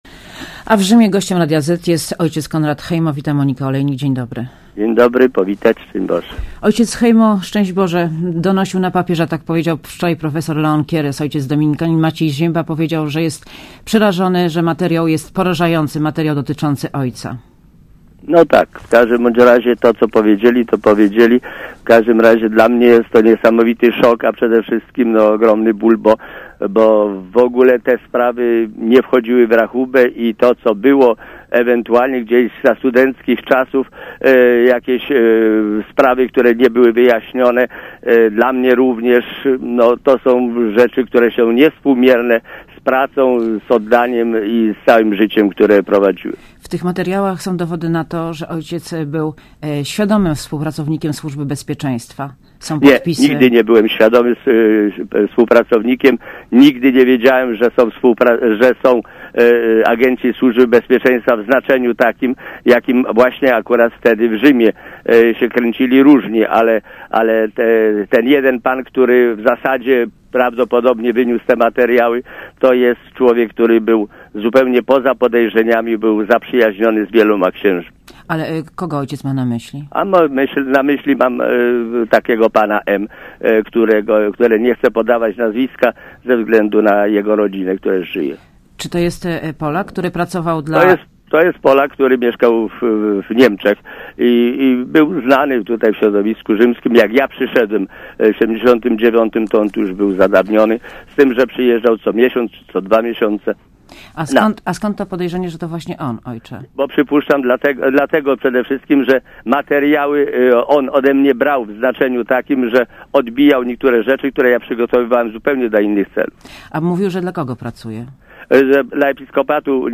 W Rzymie gościem Radia ZET jest ojciec Konrad Hejmo. Wita Monika Olejnik.
Posłuchaj wywiadu W Rzymie gościem Radia ZET jest ojciec Konrad Hejmo.